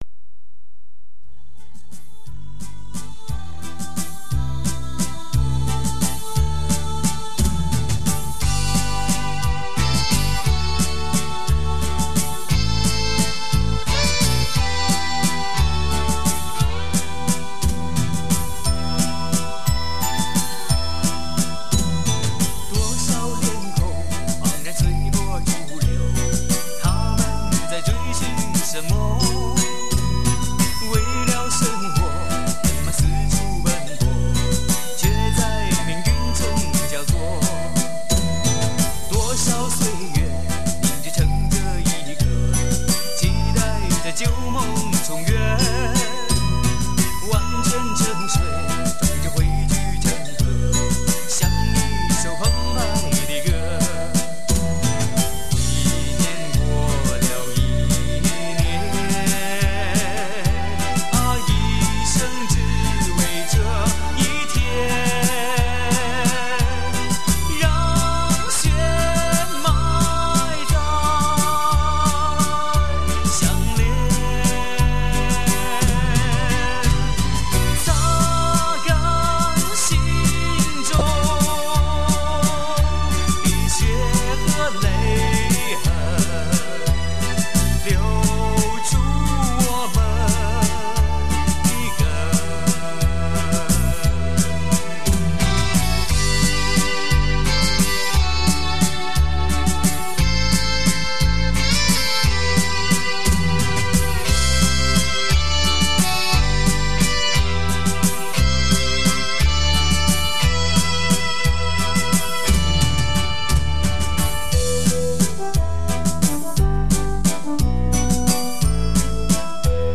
[华语流行]